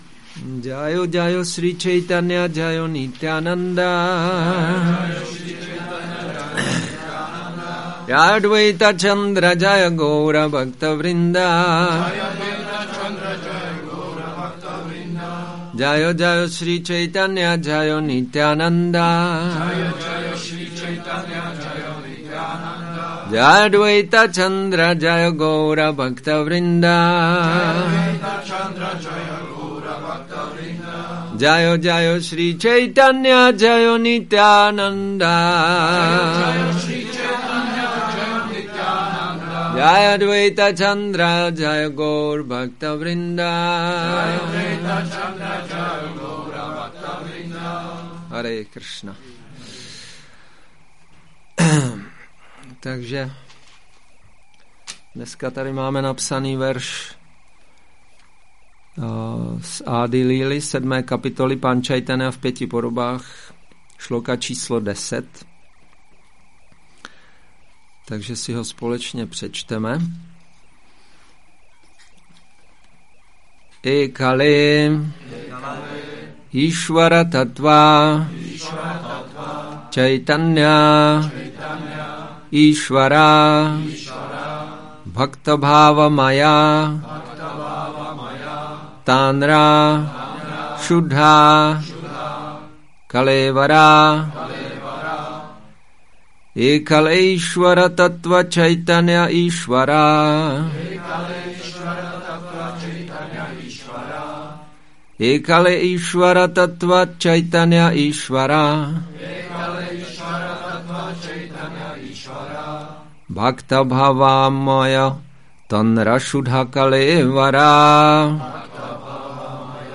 Přednáška CC-ADI-7.10 Gaura purnima